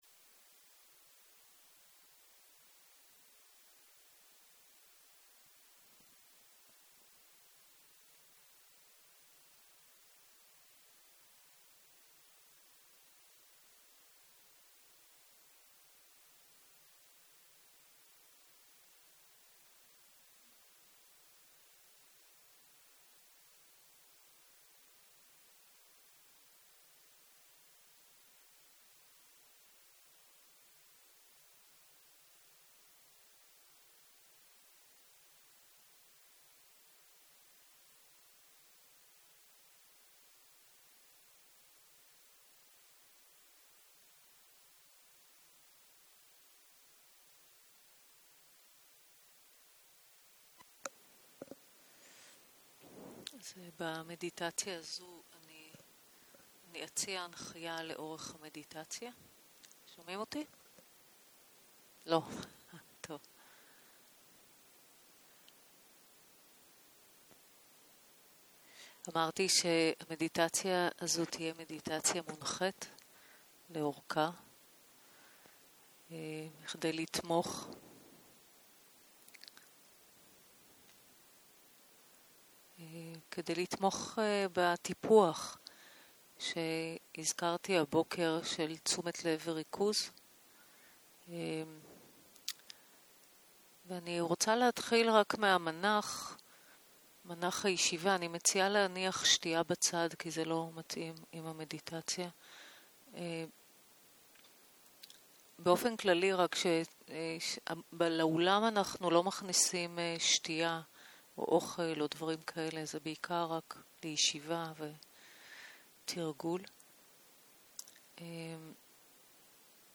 יום 2 - צהרים - מדיטציה מונחית - הקלטה 2